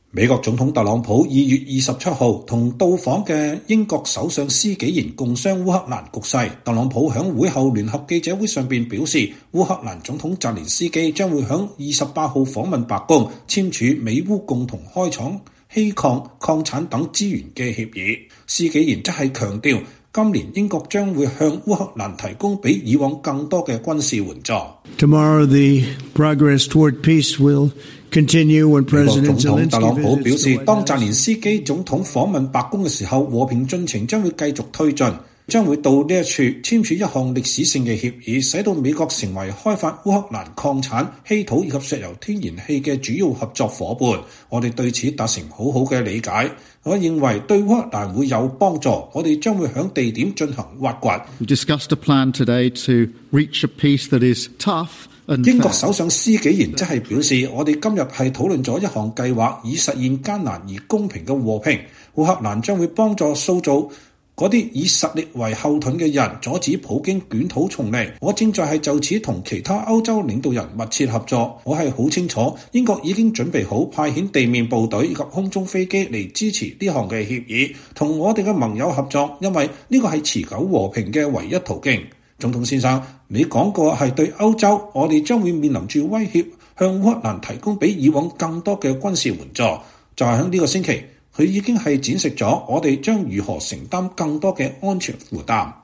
美國總統特朗普2月27日與來訪的英國首相施紀賢共商烏克蘭局勢。特朗普在會後聯合記者會上表示，烏克蘭總統澤連斯基將於28日訪問白宮，簽署美烏共同開採稀土、礦產等資源的協議。施紀賢則強調，今年英國將向烏克蘭提供比以往更多的軍事援助。